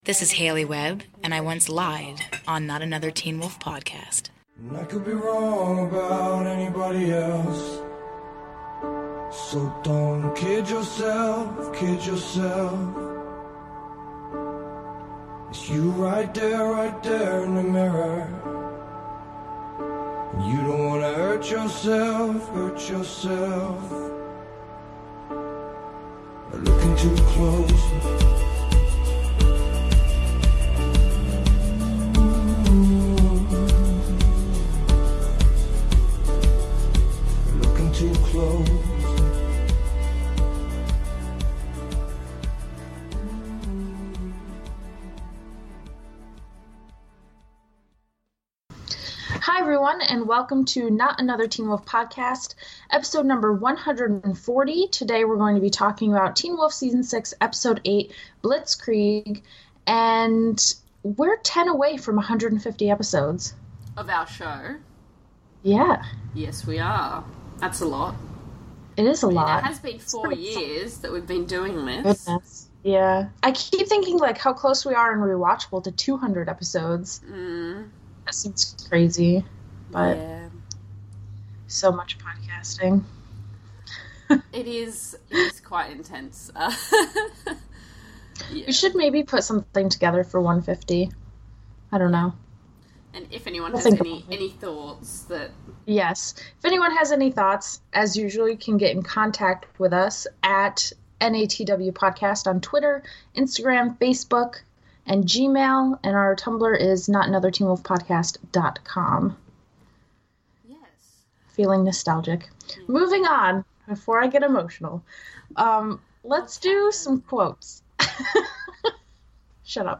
- We watch the trailer for the next episode live on air and discuss what might be coming next.